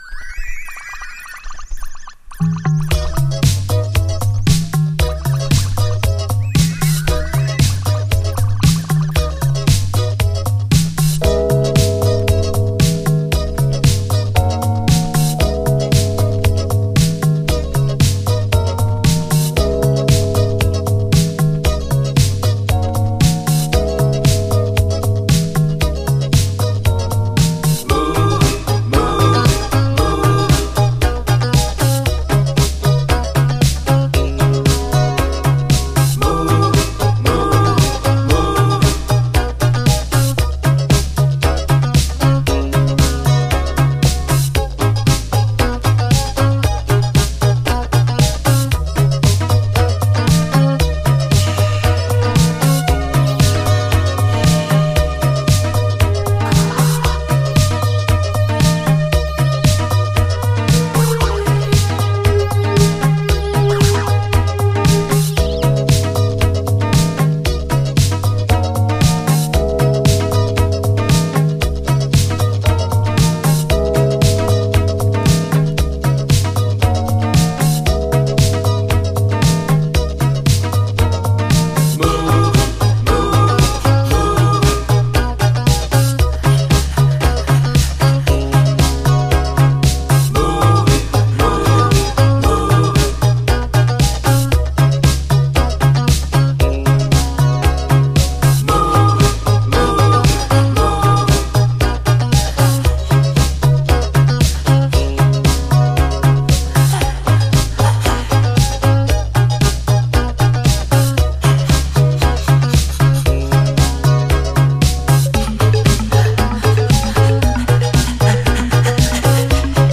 DISCO
謎めいた浮遊感のベルギー産インスト・メロウ・バレアリック・ディスコ！
両面イントロの静かな部分に溝に起因する小ノイズ入りますがイントロ以降は綺麗に聴けます。
試聴ファイルはこの盤からの録音です/　おそらくベルギーの80’Sディスコ・プロジェクト！